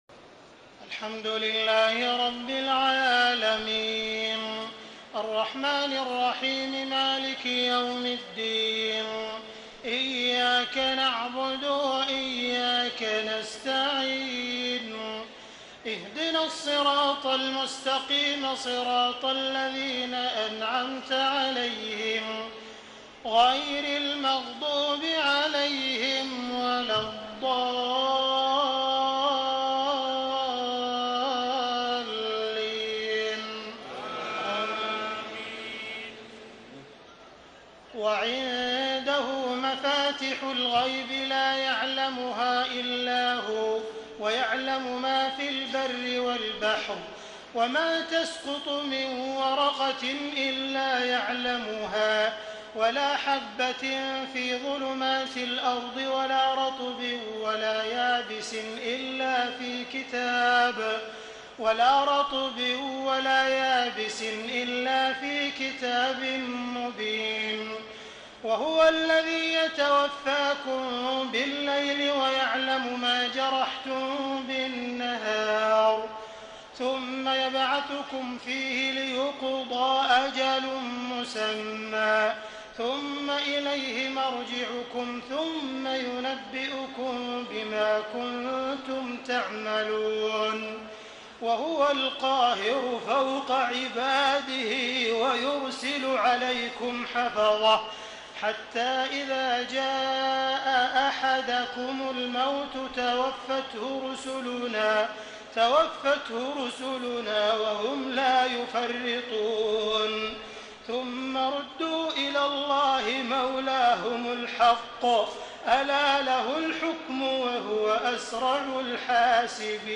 تهجد ليلة 27 رمضان 1434هـ من سورة الأنعام (59-111) Tahajjud 27 st night Ramadan 1434H from Surah Al-An’aam > تراويح الحرم المكي عام 1434 🕋 > التراويح - تلاوات الحرمين